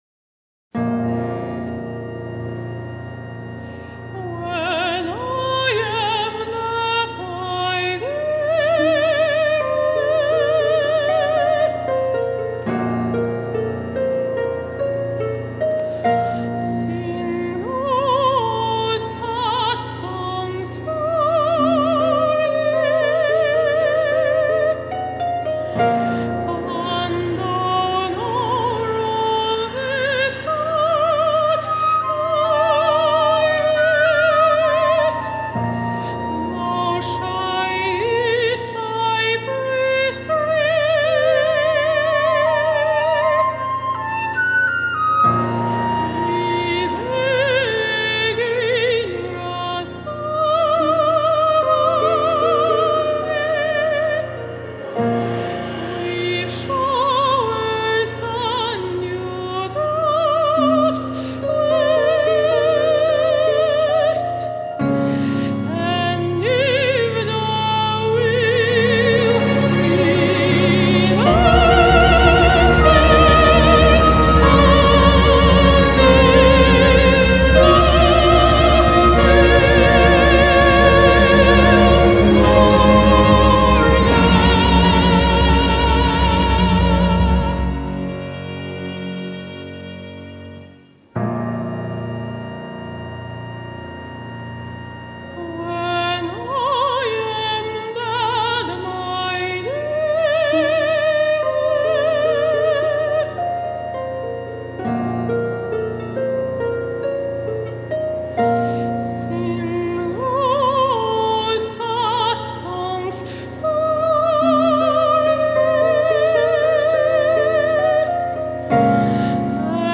18 października 2025 r. w Sali Koncertowej Wydziału Muzyki Uniwersytetu Rzeszowskiego odbyło się międzynarodowe wydarzenie artystyczne o charakterze premierowym, prezentujące widowisko muzyczne pt.
Utwór został skomponowany z myślą o rzeszowskim środowisku chóralnym i akademickim, a jego struktura łączy elementy współczesnej muzyki chóralnej, orkiestrowej oraz komponenty narracyjne właściwe dla muzyki teatralnej.
współczesne kompozycje chóralne, kompozycja chóralno-orkiestrowa